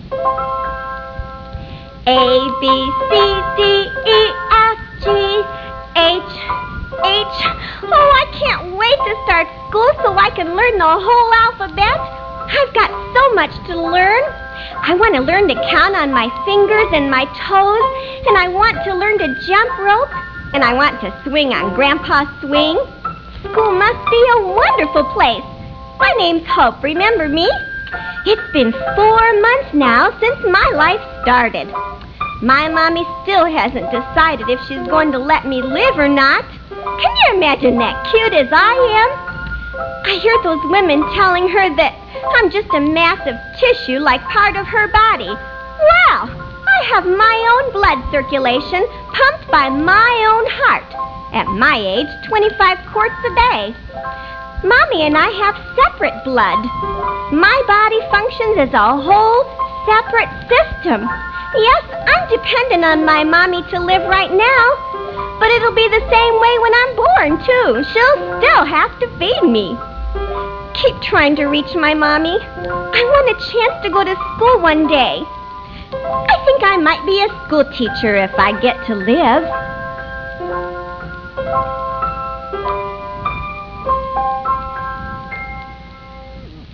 Baby Hope was originally created as commercials for a pro-life rally aired on my husband's radio program. I researched the development of a pre-born baby month by month, then became the"voice" of that baby.